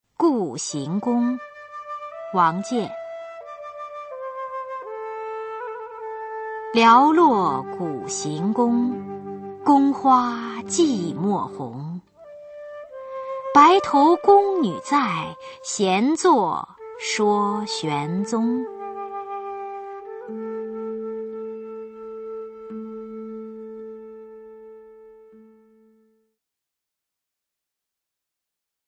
[隋唐诗词诵读]王建-故行宫 配乐诗朗诵